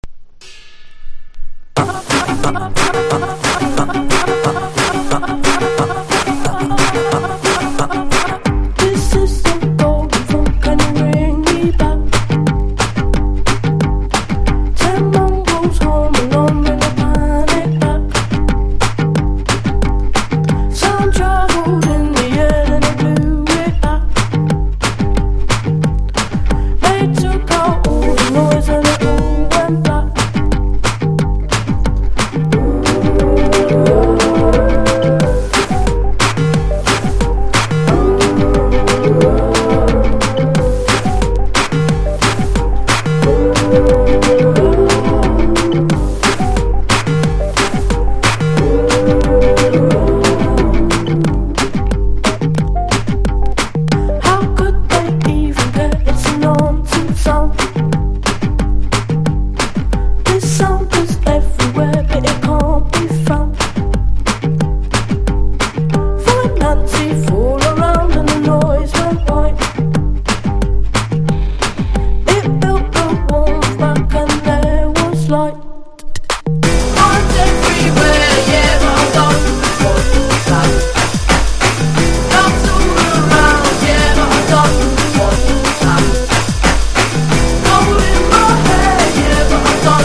NEO ACOUSTIC / GUITAR POP (90-20’s)